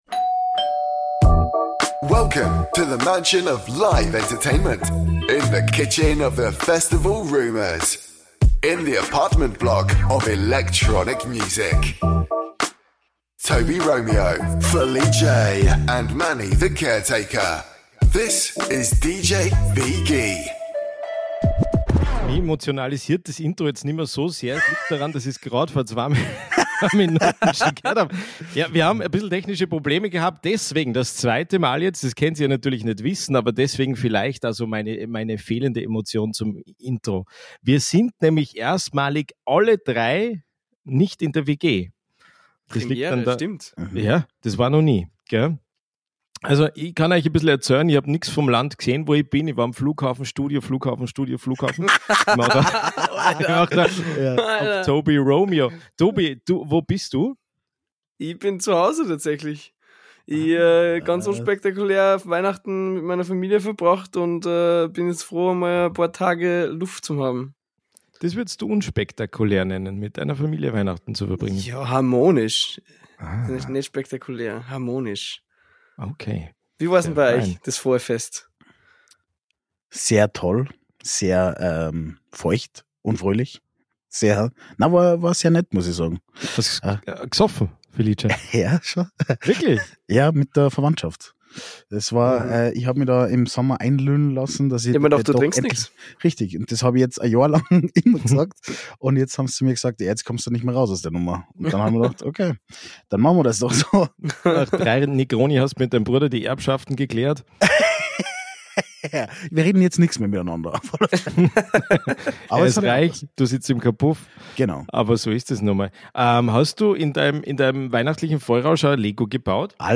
In der WG werden Heizkosten gespart - alle WG Bewohner starten Remote ins neue Jahr, mit Rückblicken und Ausblicken.